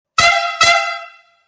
• Som agudo e de alta frequência;
Buzina de Trem Automotiva